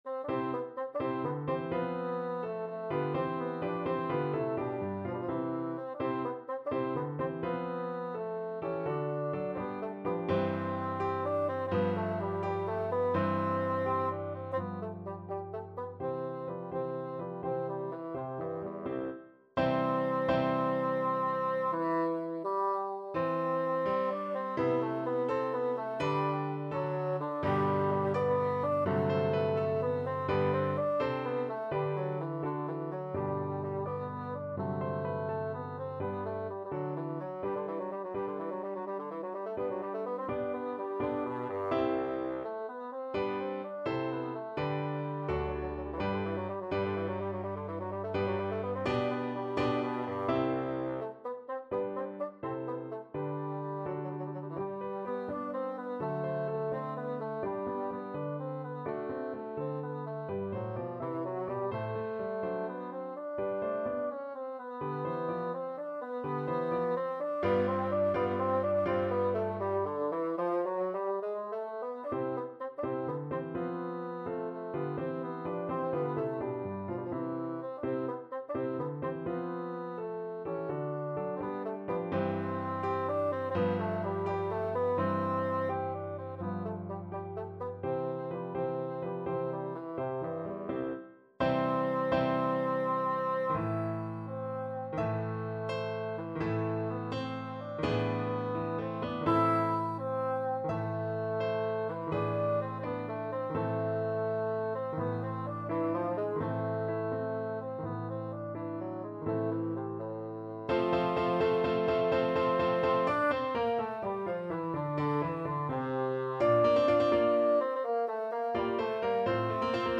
6/8 (View more 6/8 Music)
C3-E5
Grazioso .=84
Classical (View more Classical Bassoon Music)